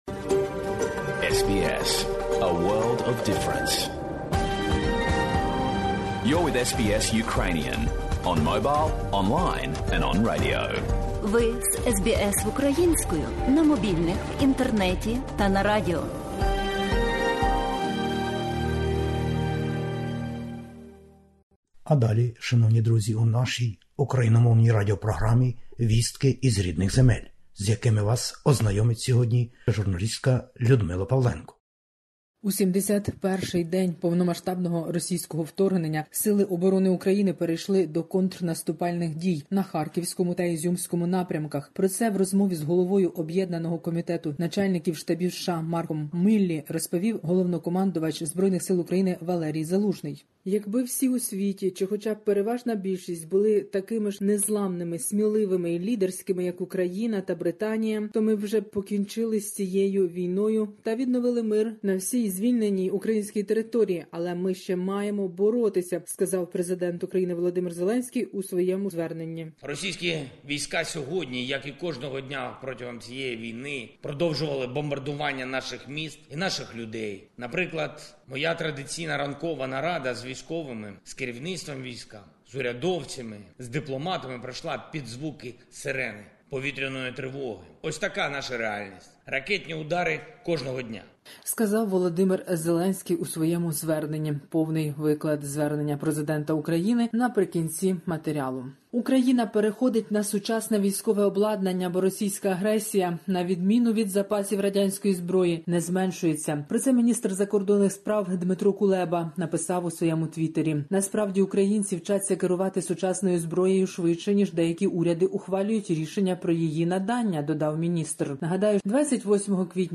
Добірка новин із героїчної України. Війна: українські війська перейшли у наступ на двох напрямках.